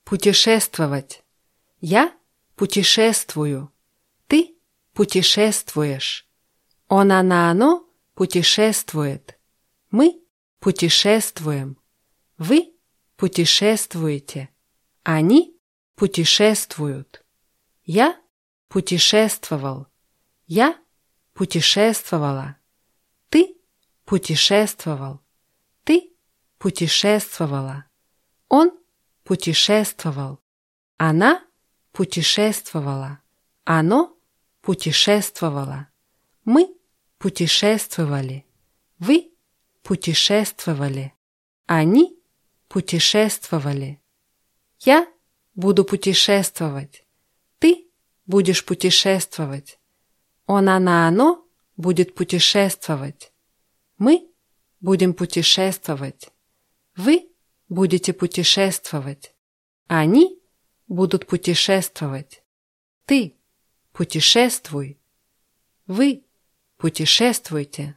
путешествовать [putʲischéßtwawátʲ]